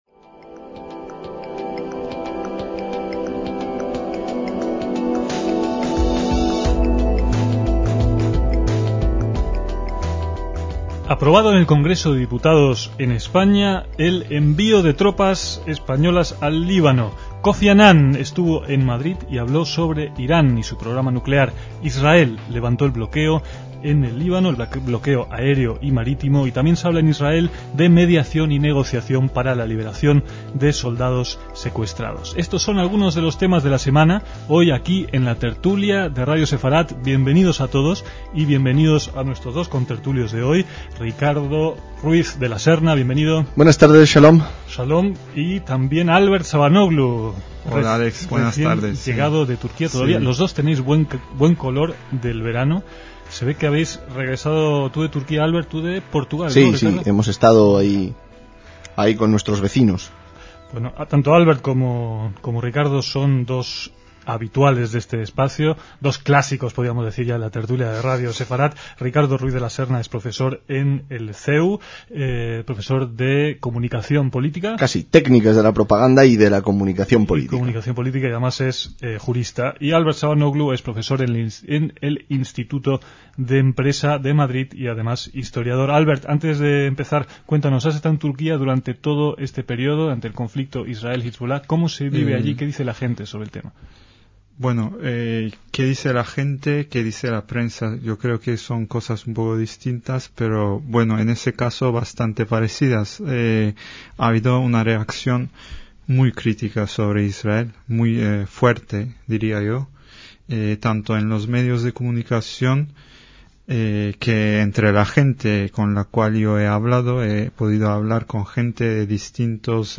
Una nueva mesa redonda en torno a la actualidad informativa